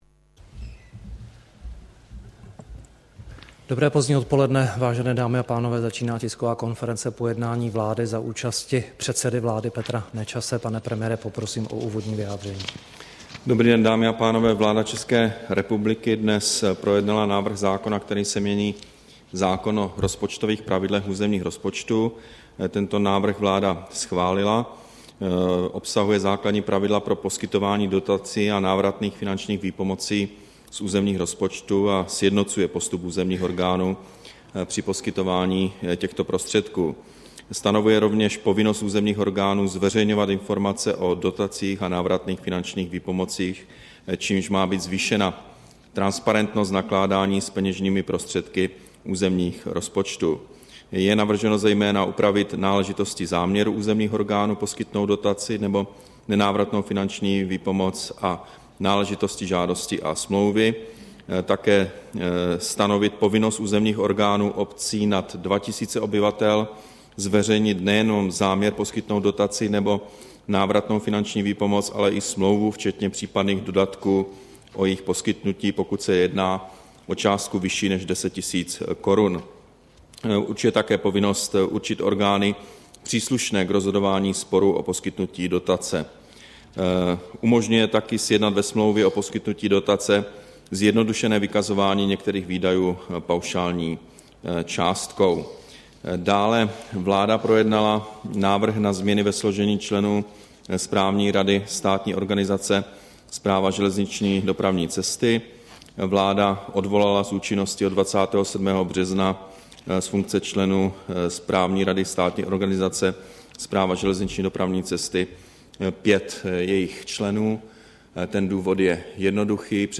Tisková konference po jednání vlády, 27. března 2013